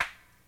TC Clap Perc 01.wav